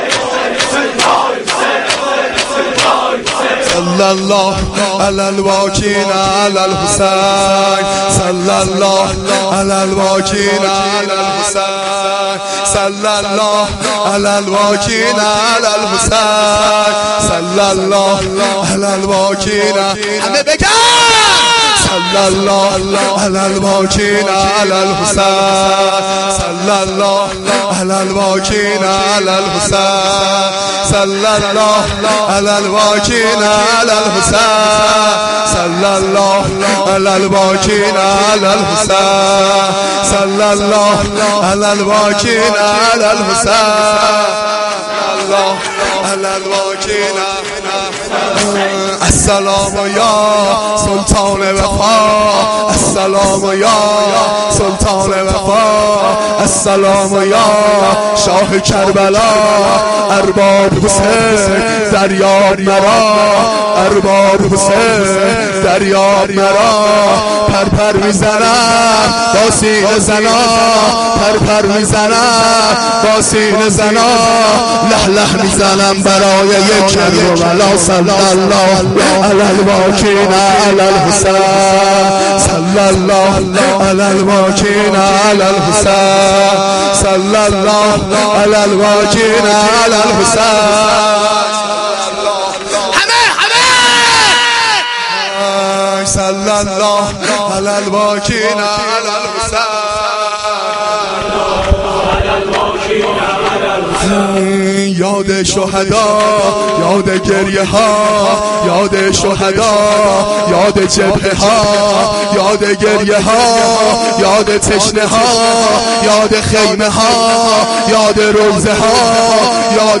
مداحی